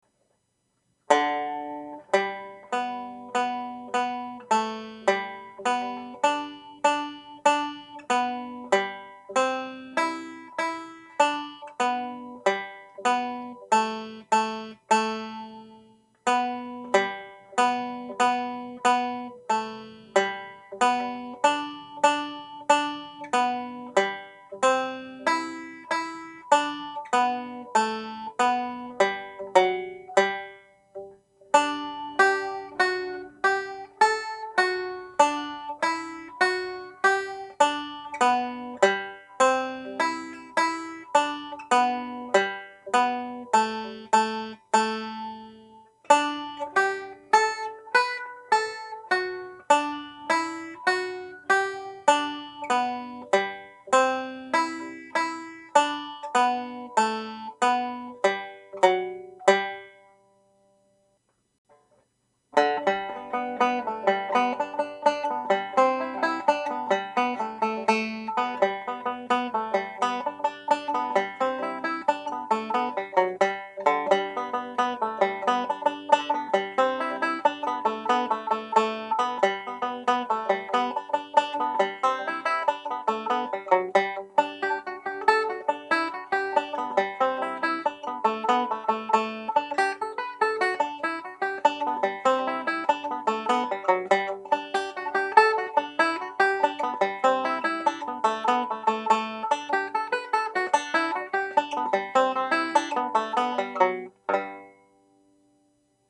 Jigs
(G Major)